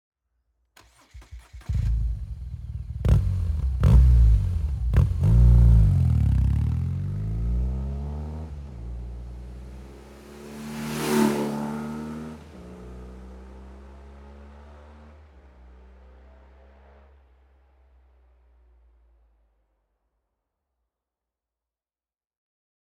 BMW GS SOUNDCHECK
Mr. Hyde Mode - The exhaust boosts a beautiful, deep rumble.